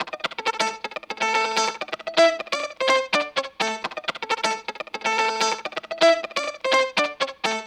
Electric Guitar 06.wav